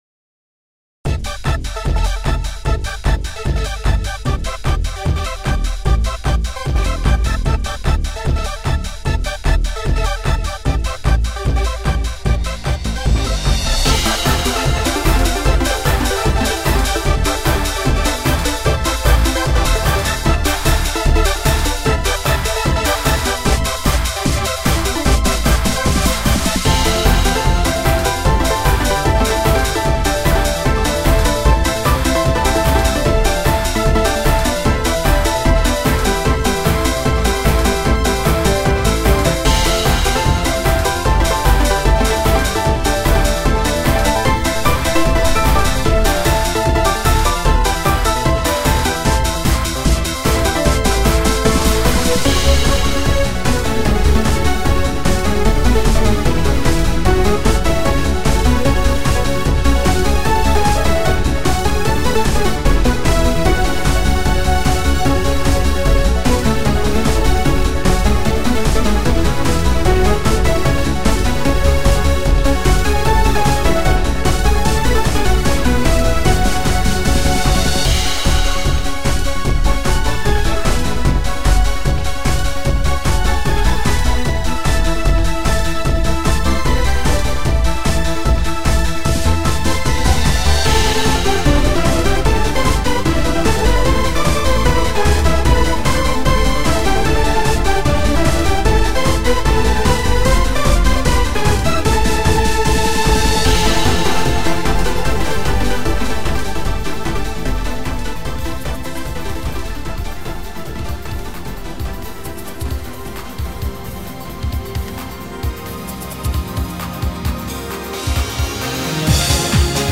さって、ようやくと作業が一段落しましたので、クロスフェードデモを公開しときます。